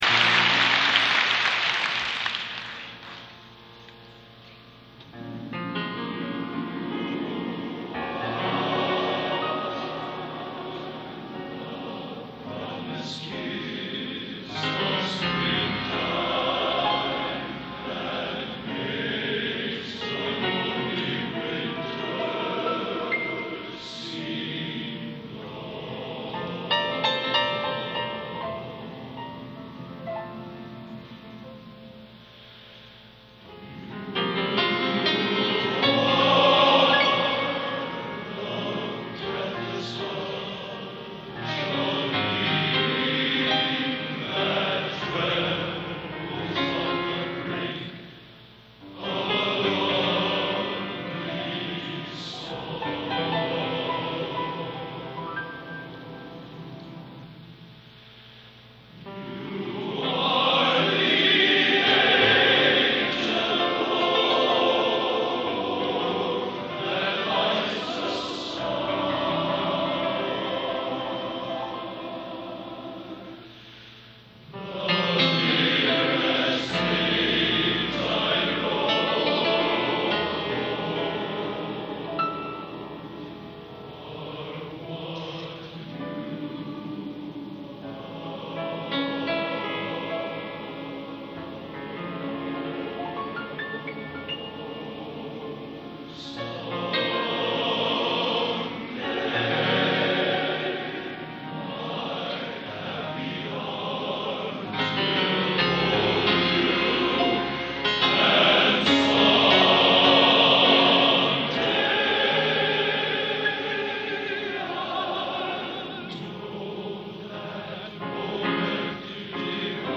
Genre: Popular / Standards Schmalz | Type: